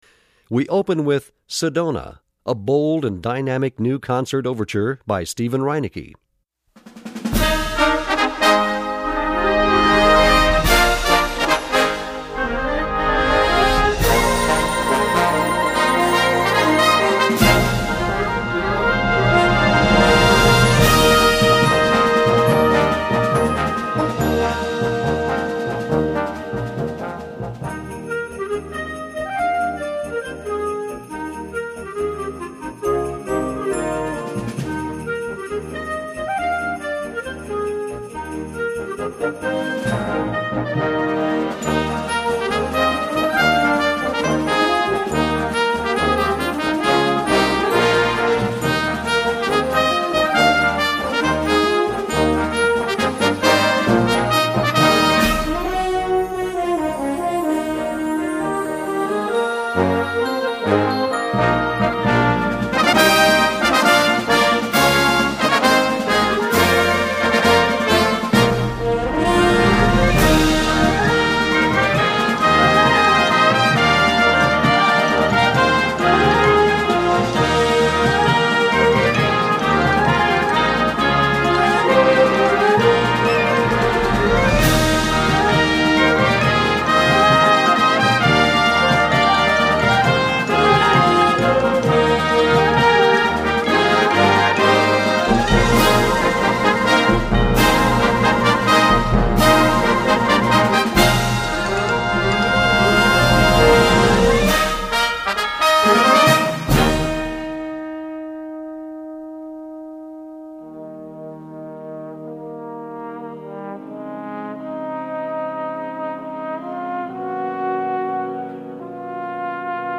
Gattung: Konzertwerk
Besetzung: Blasorchester
Die kühne und dynamische Komposition